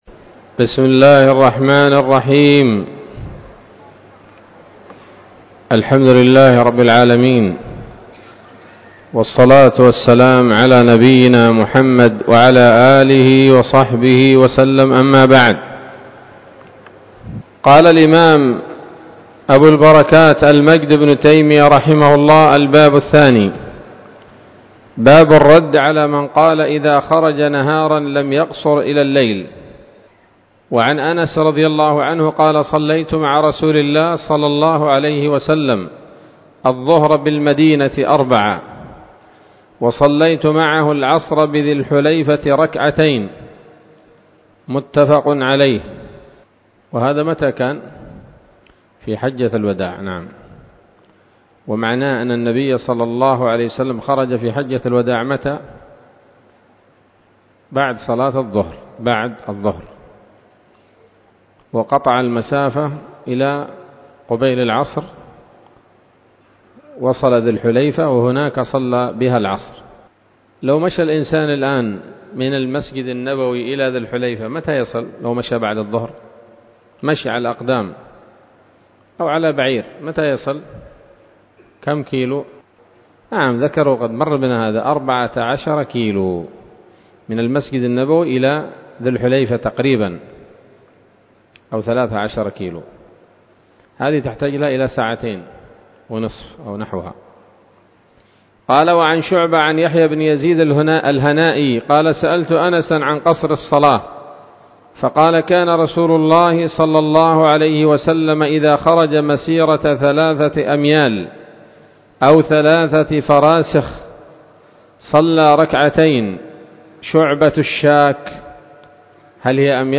الدرس الرابع من ‌‌‌‌أَبْوَاب صَلَاةِ الْمُسَافِرِ من نيل الأوطار